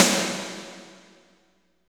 49.01 SNR.wav